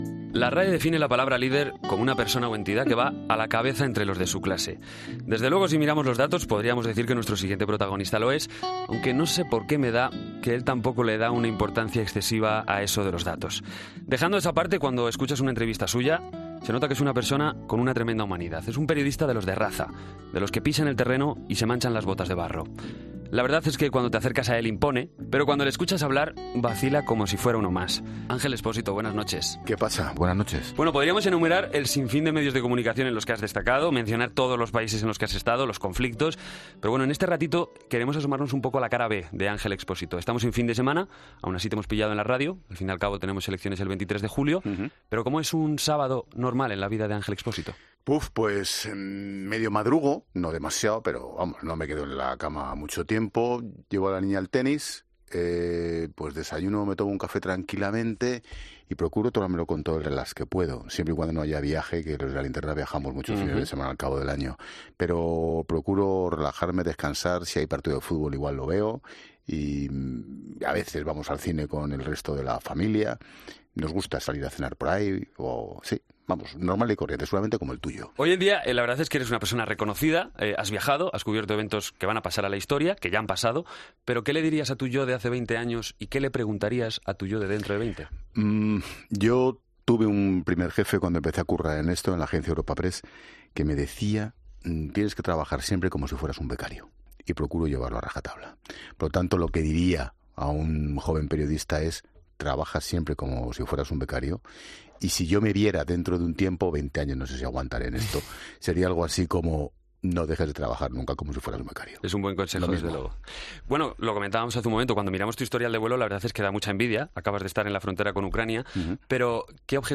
AUDIO: Escucha la entrevista completa a Ángel Expósito en el programa especial de COPE 'Lo que Nadie Sabe'